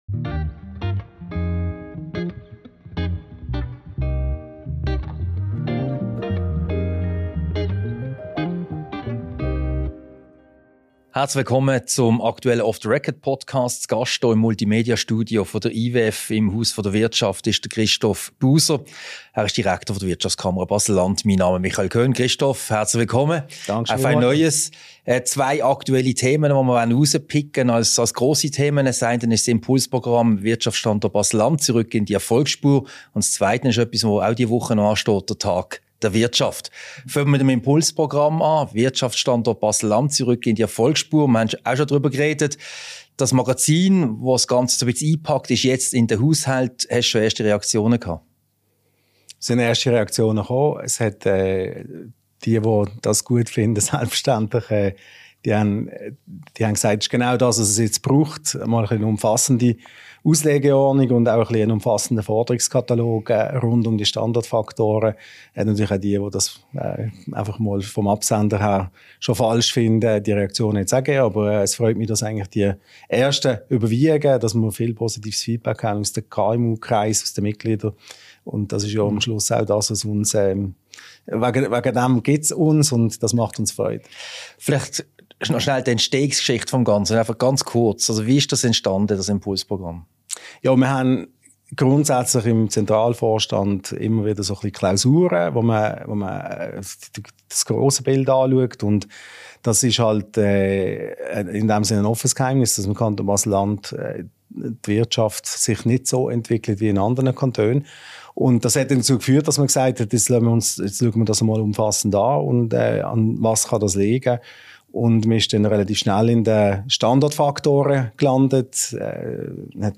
Diese Podcast-Ausgabe wurde als Video-Podcast im Multimedia-Studio der IWF AG im Haus der Wirtschaft HDW aufgezeichnet.